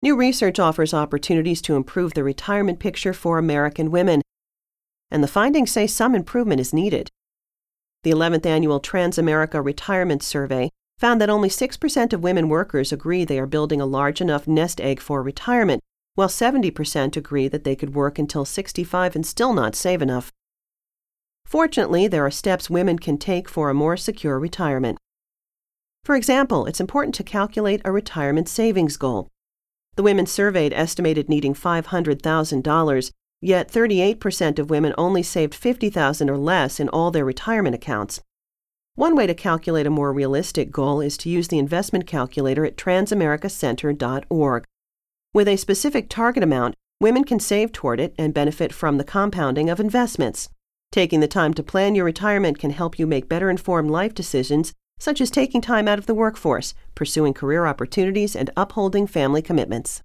TCRS produced this audio news release to help increase public awareness of the Saver's Credit, an important tax credit for low- to middle-income tax filers who save for retirement through a qualified retirement plan, such as a 401(k), or IRA. The audio news release is designed for a wide general audience and for use in radio broadcasts.